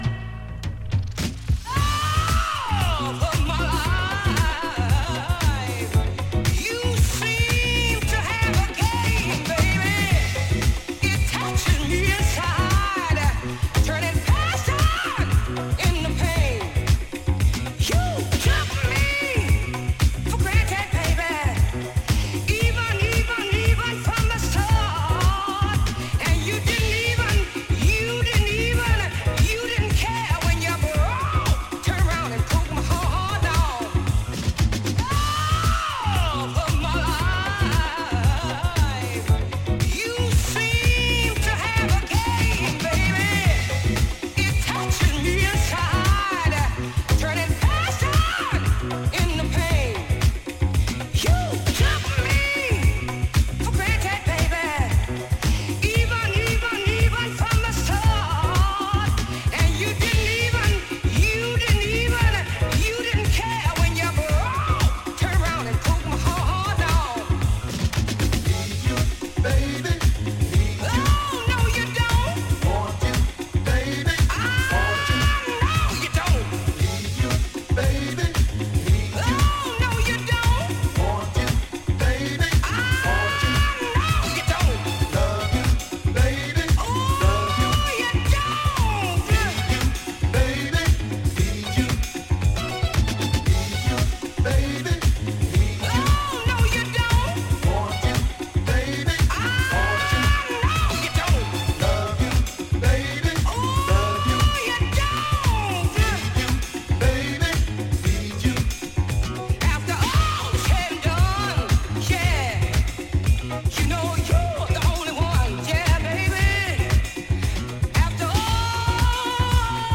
rooted in soul, jazz and groove.
emotive vocals
signature flair for rhythmic depth and dancefloor flow